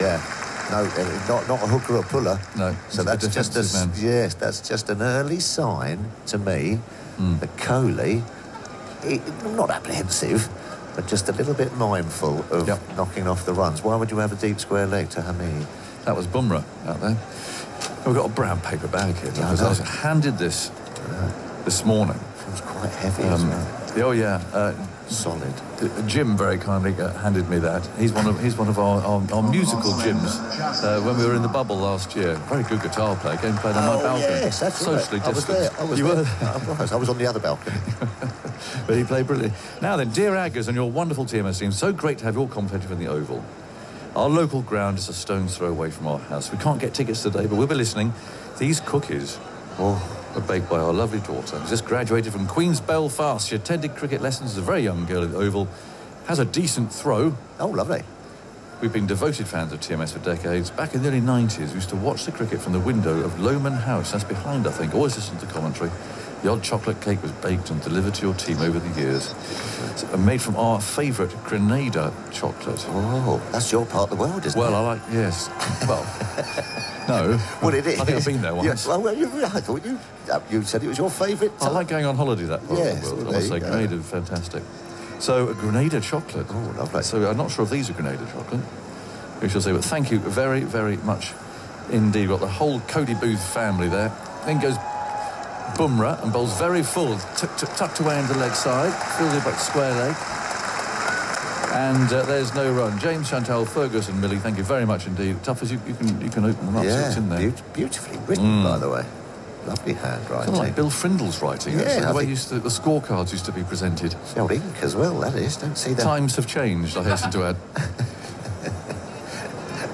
You can hear the joy in the commentary that followed, as Aggers and Phil Tufnell (Tuffers) bantered over the delivery.
Angers-Tuffers-TMS.m4a